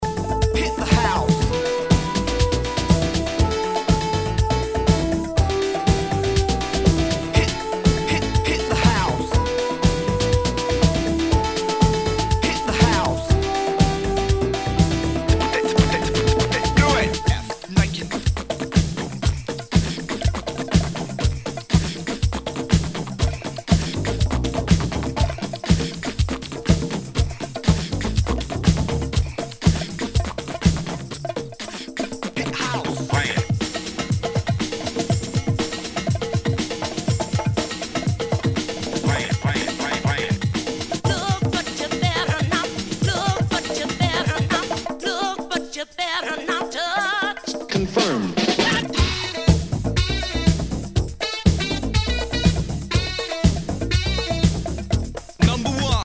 HOUSE/TECHNO/ELECTRO
ナイス！ハウス・クラシック！